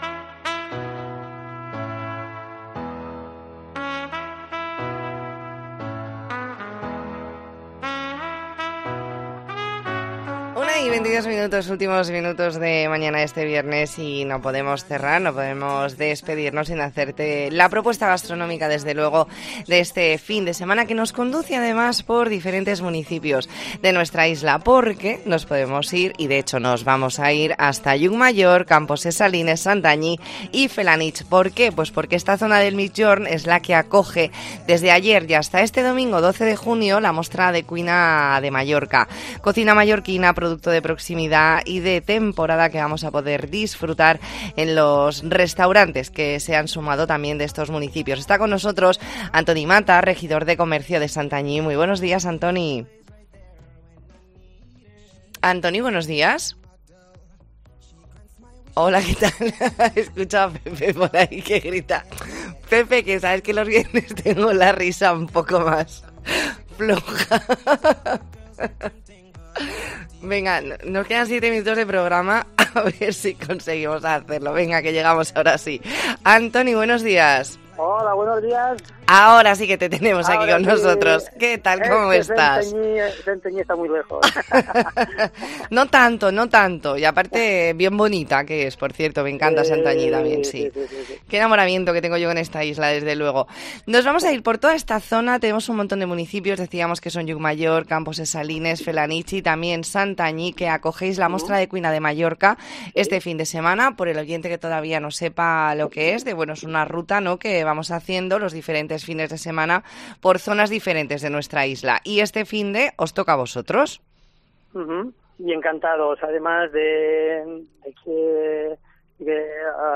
Redacción digital Madrid - Publicado el 10 jun 2022, 13:20 - Actualizado 18 mar 2023, 16:18 1 min lectura Descargar Facebook Twitter Whatsapp Telegram Enviar por email Copiar enlace Hablamos con Antoni Matas, regidor de comercio de Santanyí.